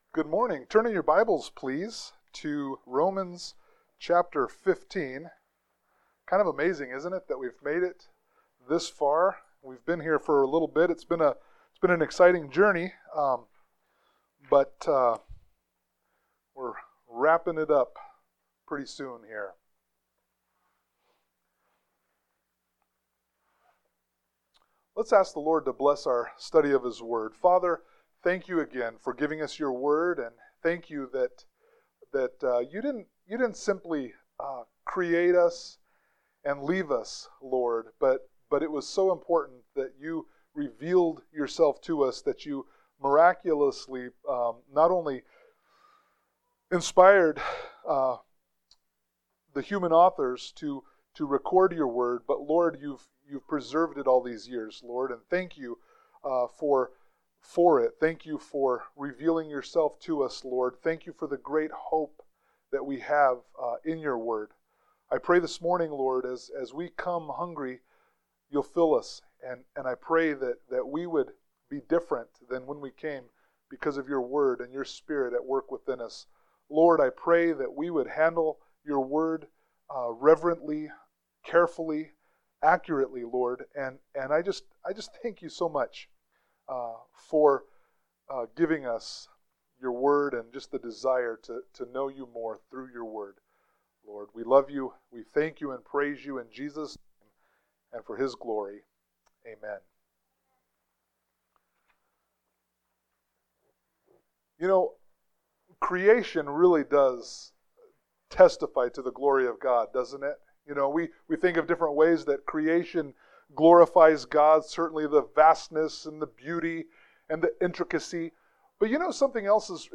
Romans 15:1-7 Service Type: Sunday Morning Worship « Romans 14:13-23 Easter Service 2021